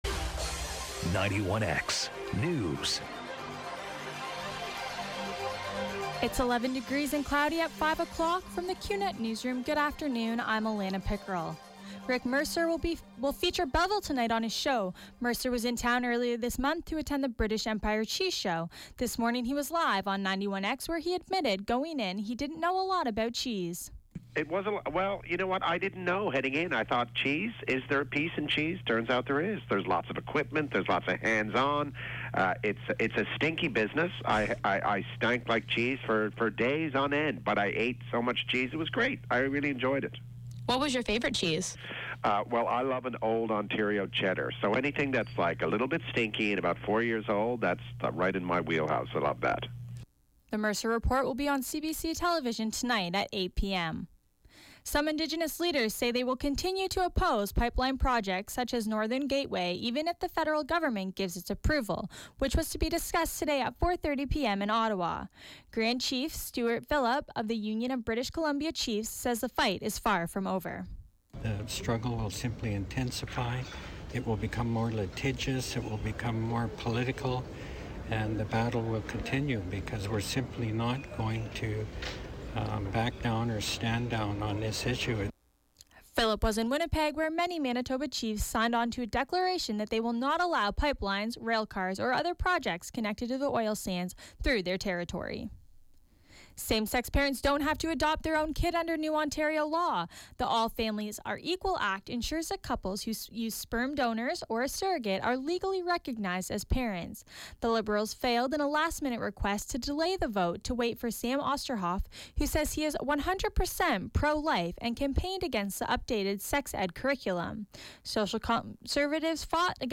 91X FM Newscast – Tuesday, Nov. 29, 2016, 5 p.m.